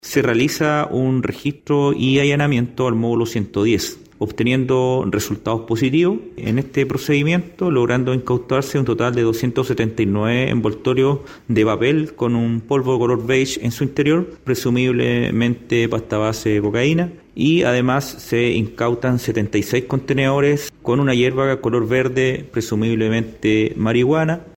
Quien entregó detalles del procedimiento y de los elementos que fueron decomisados desde las celdas fue el coronel Luis González, director de Gendarmería en la región de Valparaíso.